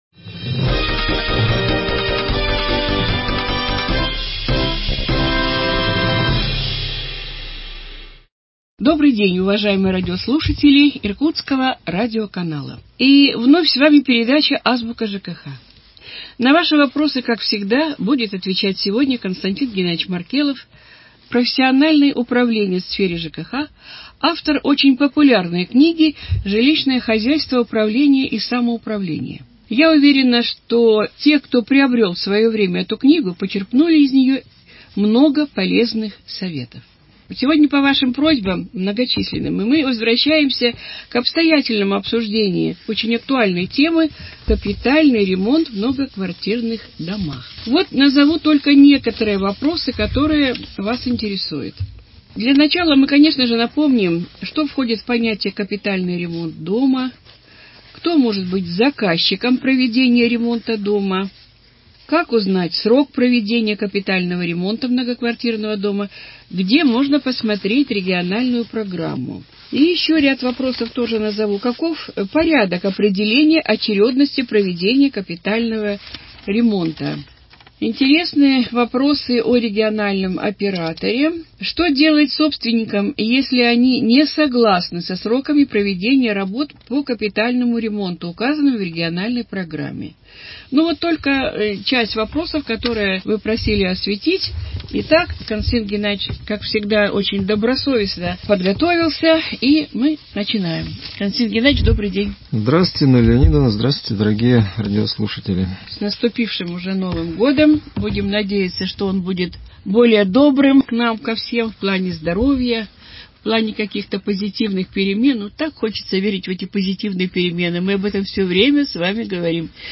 отвечая на конкретные вопросы радиослушателей. Главная тема беседы - капитальный ремонт многоквартирных домов.